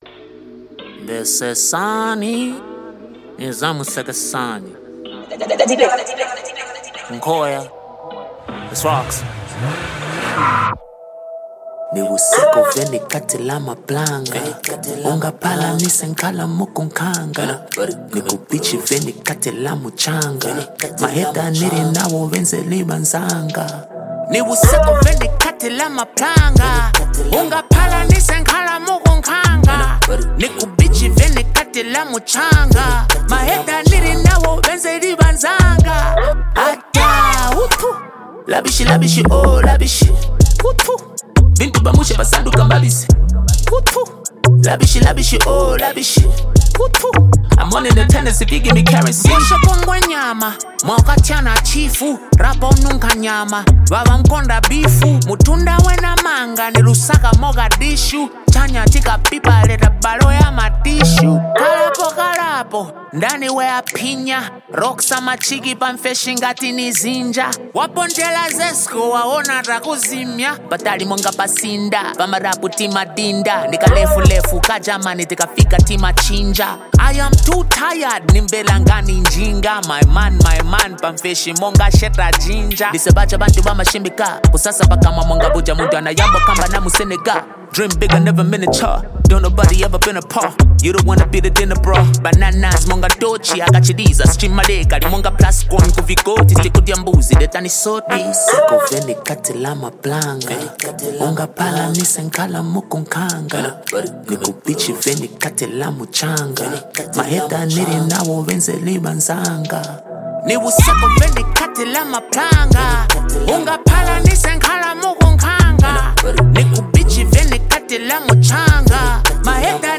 feel-good energy and irresistible rhythm